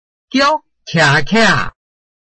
臺灣客語拼音學習網-客語聽讀拼-饒平腔-開尾韻
拼音查詢：【饒平腔】kia ~請點選不同聲調拼音聽聽看!(例字漢字部分屬參考性質)